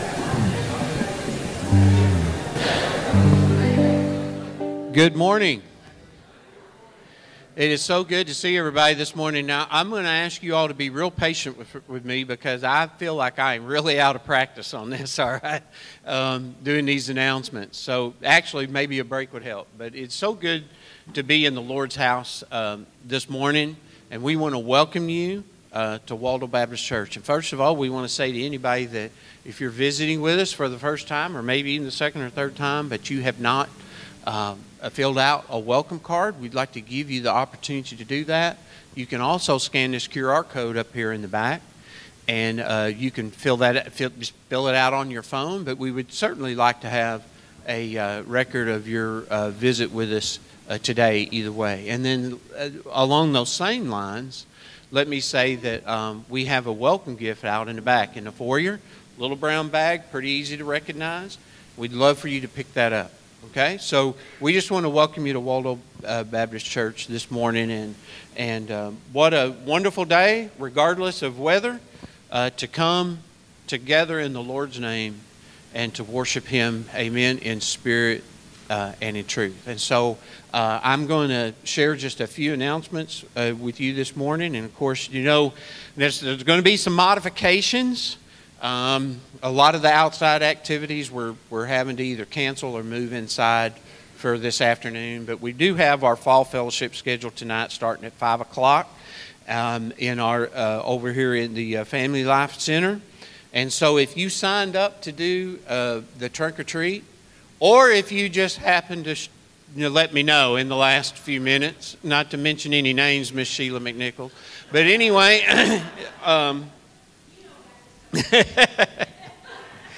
Sermon Manuscript Series Scripture Guide